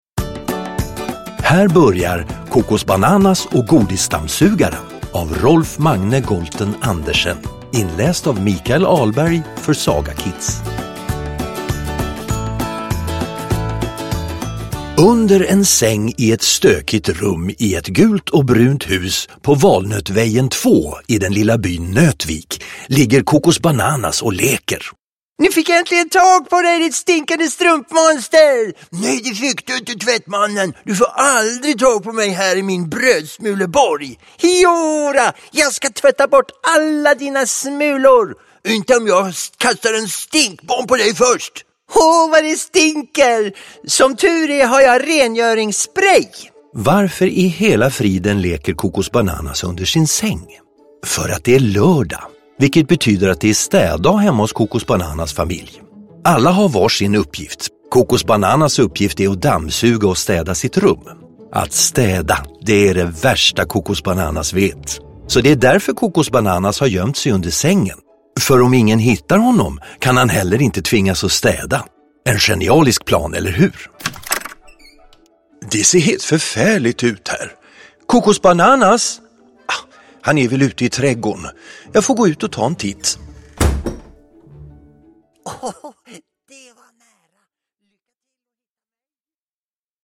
Ljudbok
Med härliga ljudeffekter och musik bjuder Kokosbananas på underhållning för hela familjen!